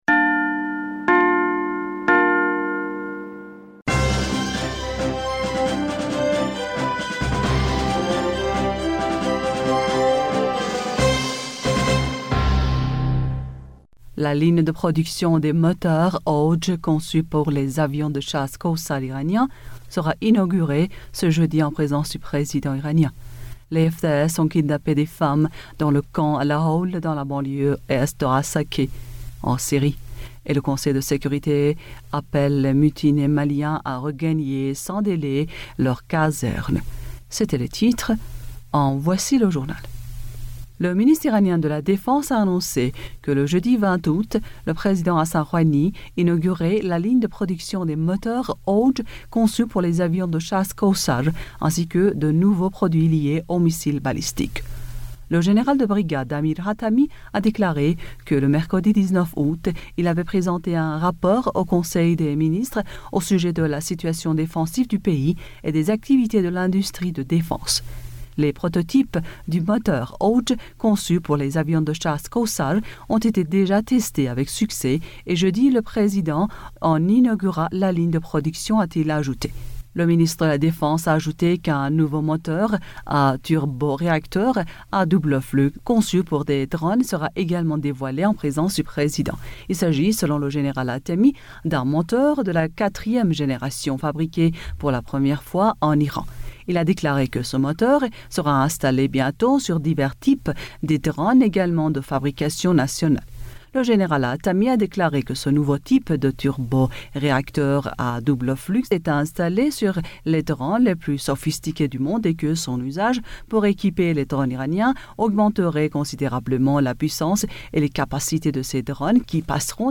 Bulletin d'information du 20 Aout 2020